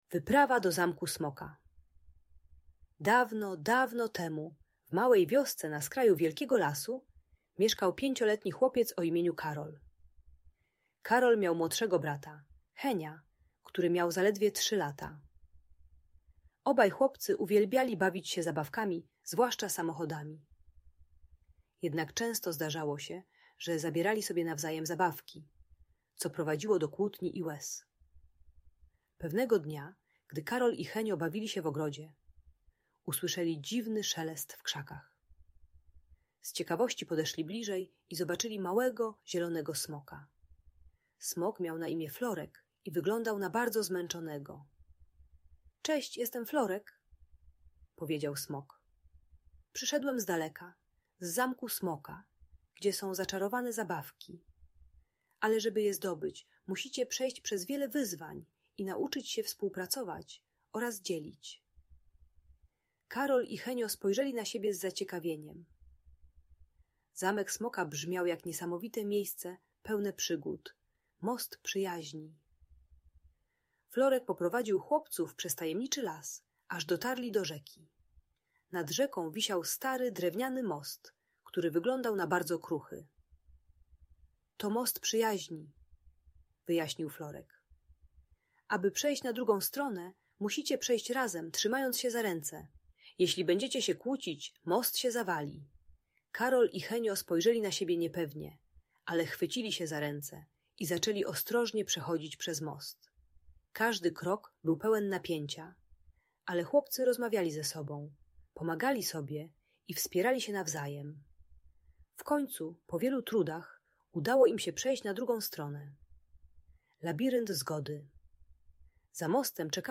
Wyprawa do Zamku Smoka - Opowieść o Przyjaźni i Współpracy - Audiobajka